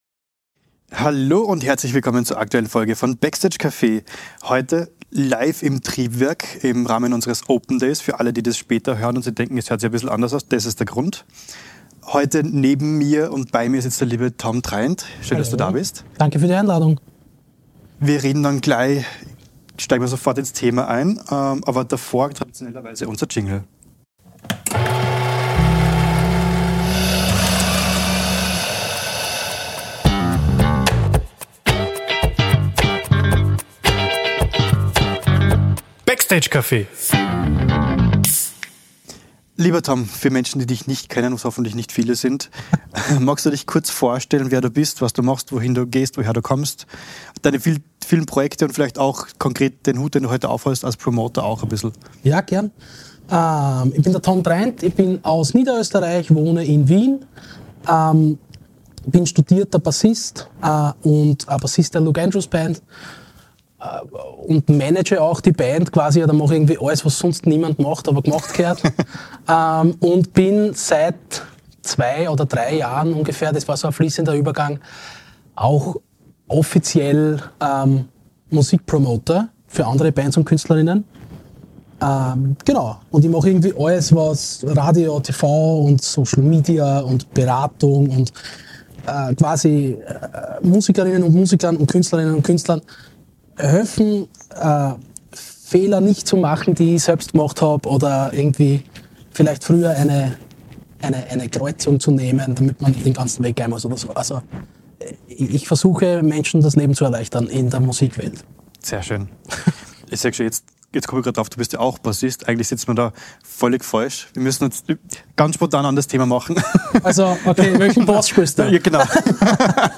Besonders spannend: Unsere Mitglieder waren beim Live-Recording dabei, konnten direkt Fragen stellen und sich aktiv einbringen.